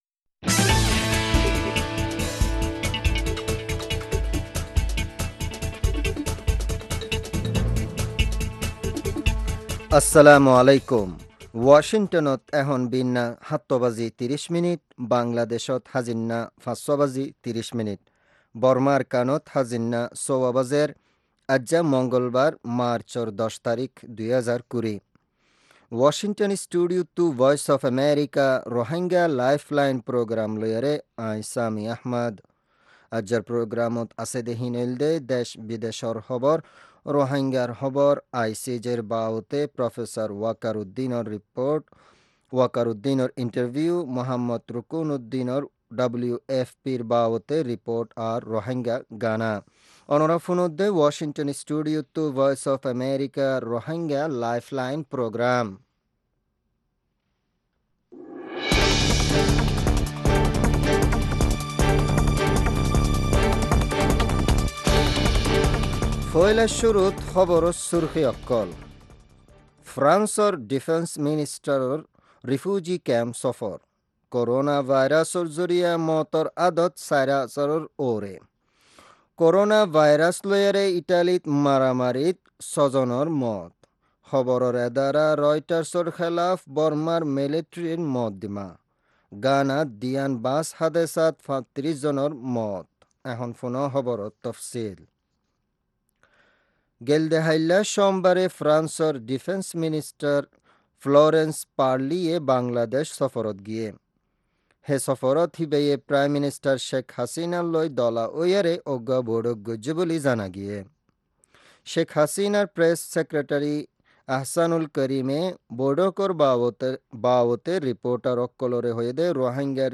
Rohingya Broadcast 03.10.2020